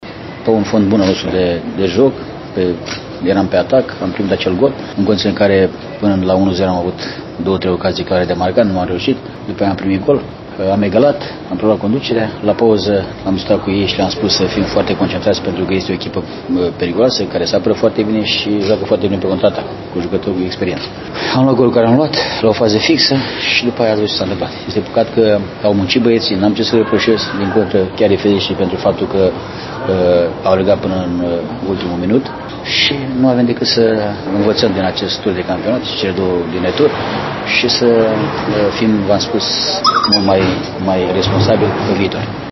Antrenorul buzoienilor, Ilie Stan, a comentat evoluția ”nebună” a scorului: